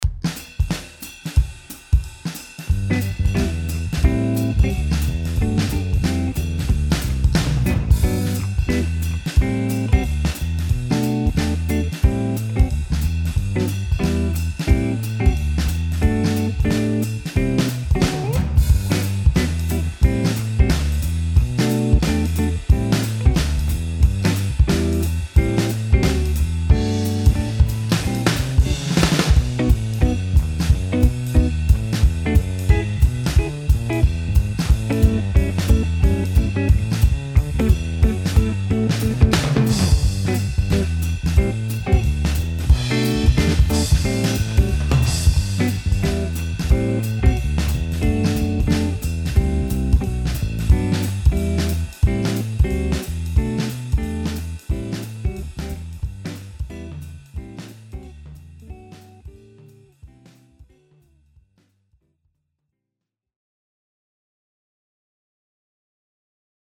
backing